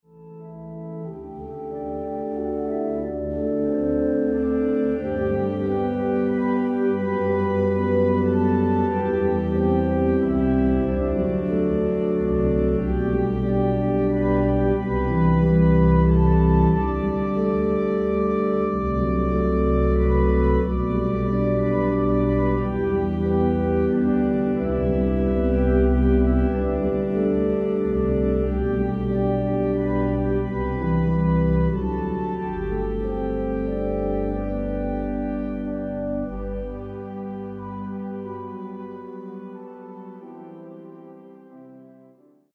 ●リードオルガン 楽譜(手鍵盤のみで演奏可能な楽譜)